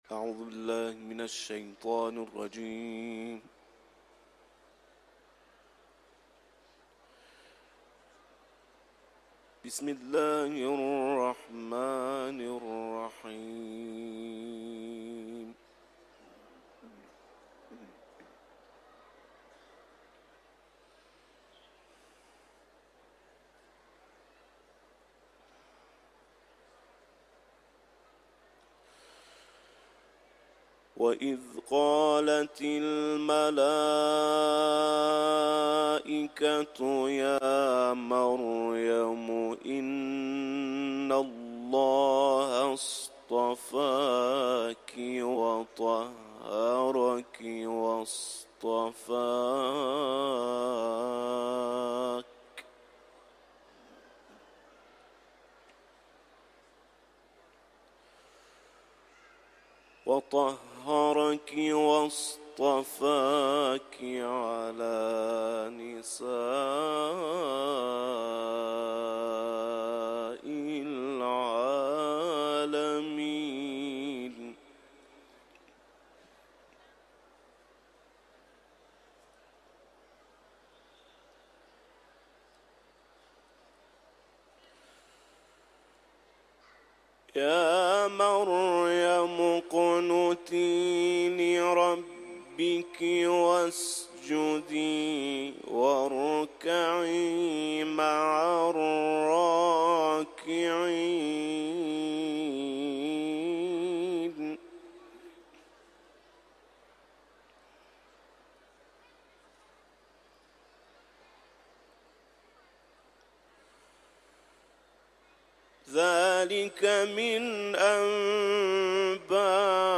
Etiketler: Kuran tilaveti ، İranlı kâri ، ayet